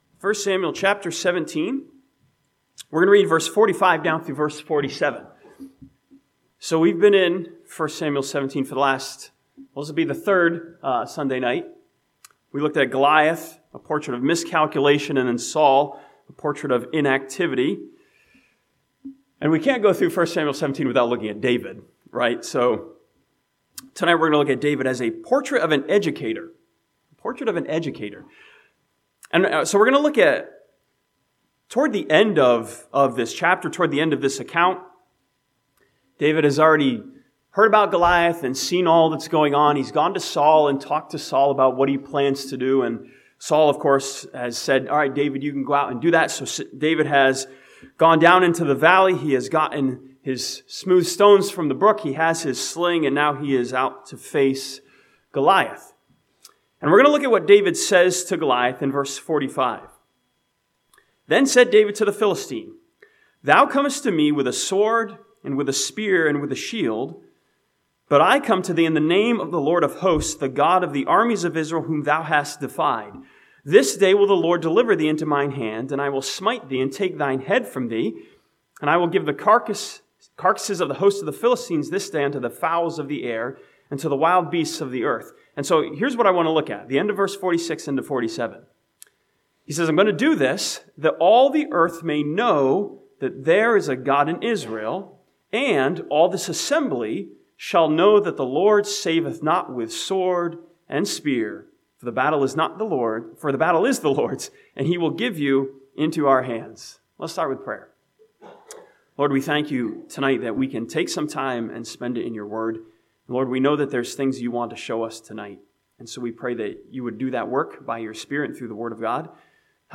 This sermon from 1 Samuel chapter 17 challenges believers to follow David as a portrait of an educator who taught his audience about God.